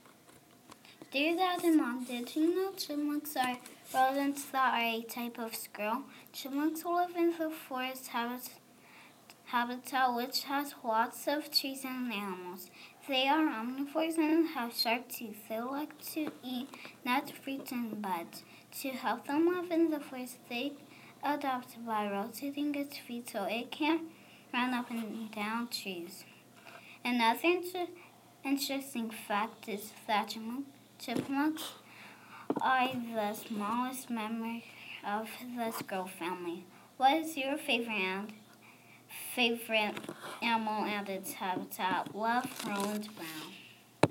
Chipmunks